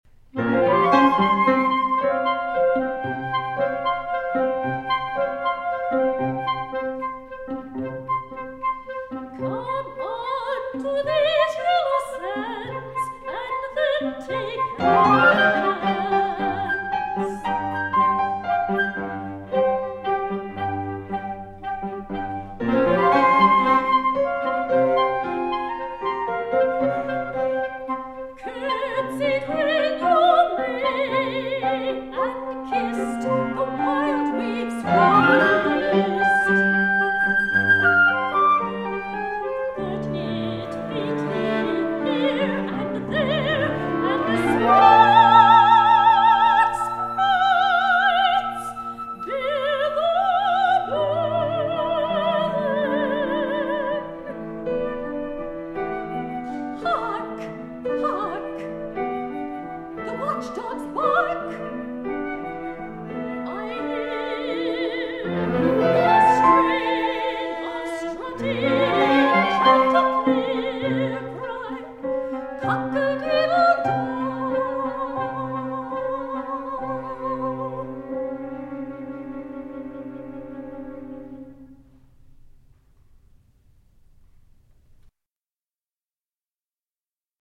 Voicing: Soprano Voice